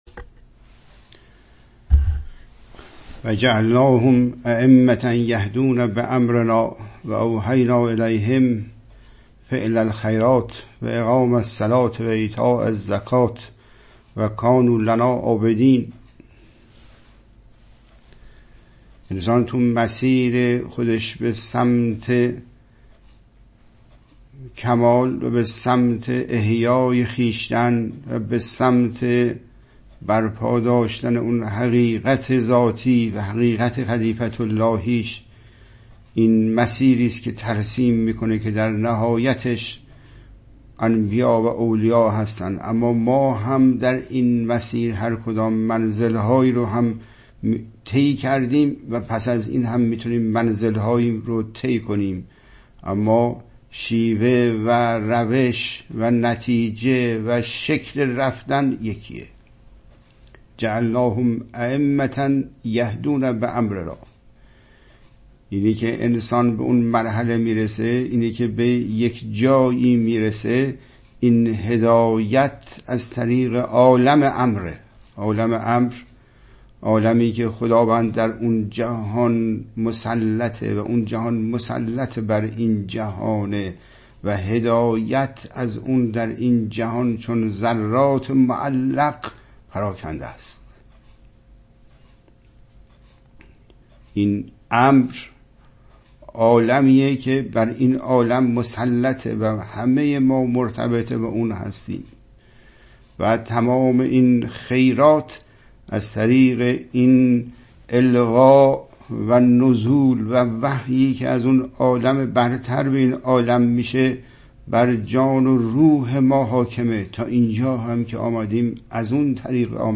برنامه جدید مواجهه با دنیای قرآنی اگرچه ادامه برنامه‌های قبلی است، اما مرتبه کامل‌تر آن بوده و از جهاتی با برنامه‌های قبلی متفاوت است: ۱. طبق روال قبلی برنامه انلاین آن با پخش آیاتی از قرآن مجید آغاز شده و حاضرین با مخاطبه و تکلیم با متن به توجه به نفس می‌پردازند.
۴. در جلسه ارائه شفاهی صورت خواهد گرفت ولی سوال مکتوب یا شفاهی طرح نخواهد شد.